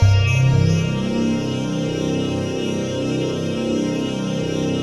ATMOPAD18 -LR.wav